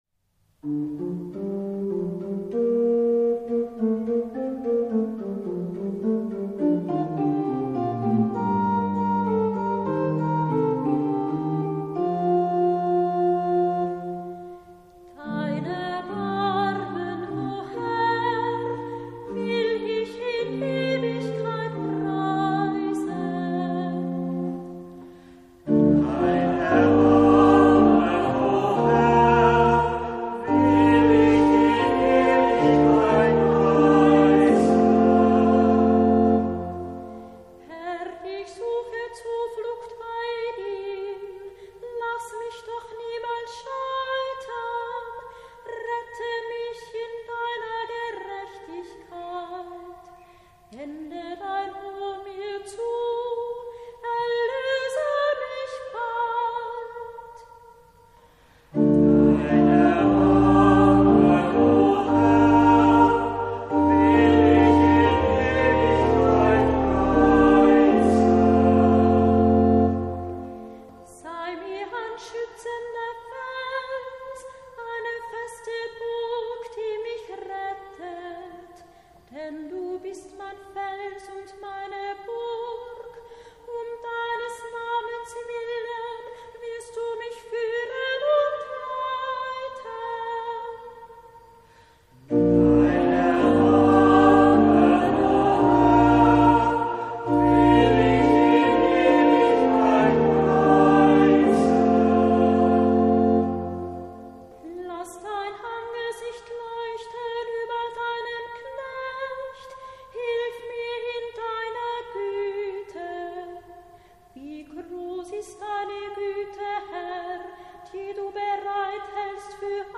Psalmton 8 Orgel
Gesang
psalm_31_psalmton_8.mp3